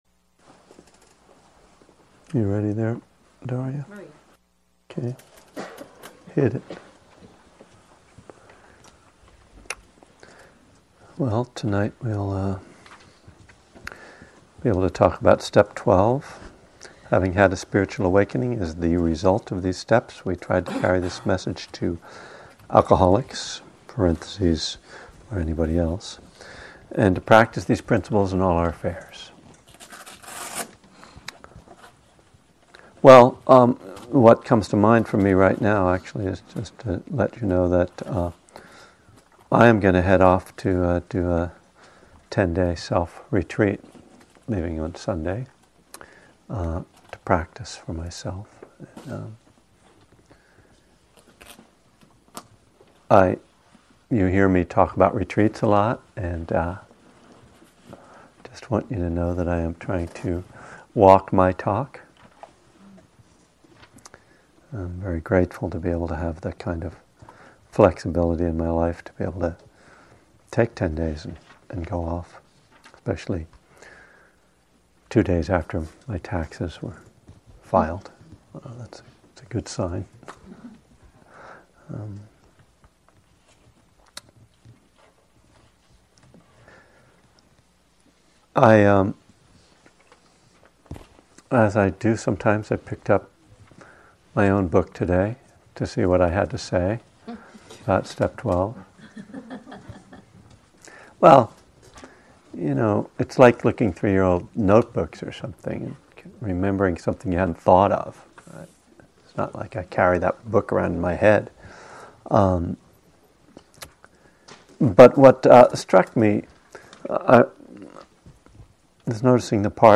From a Buddhism and the Twelve Steps class series at Spirit Rock Meditation Center in Spring 2011.